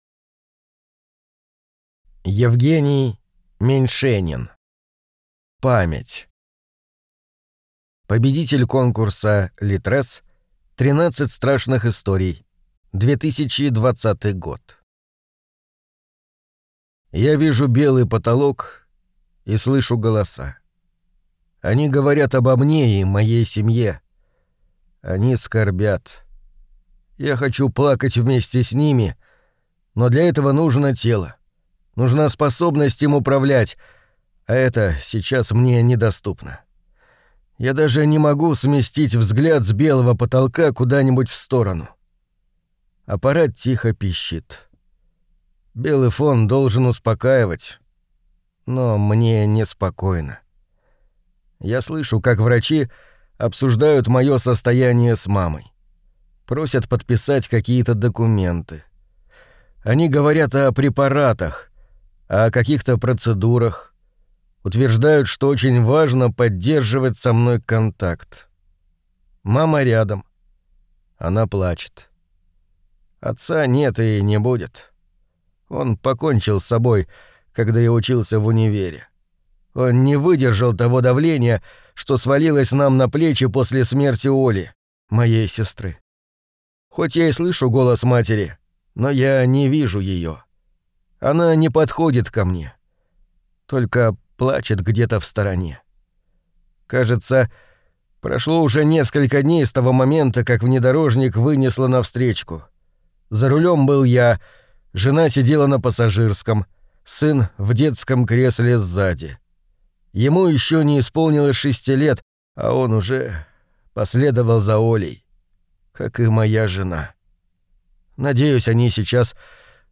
Аудиокнига Память | Библиотека аудиокниг